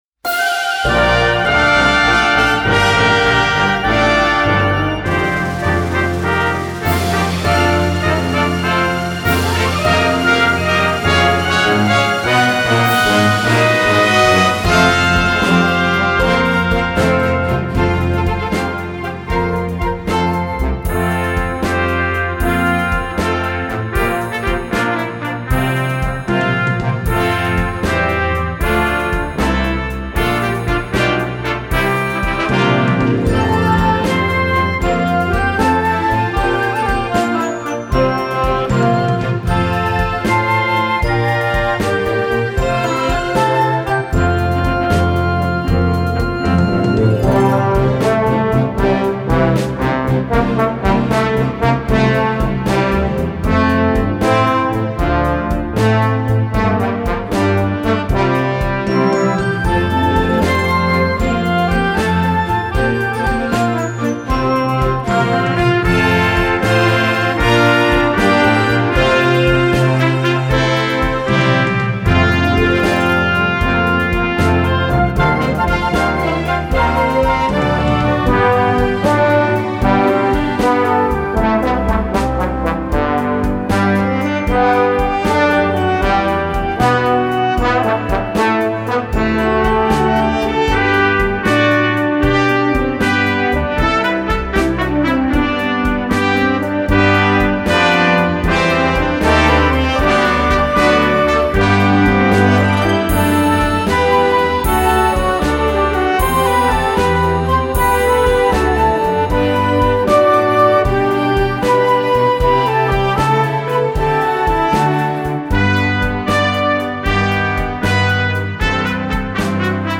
Genre: Christian Instrumental.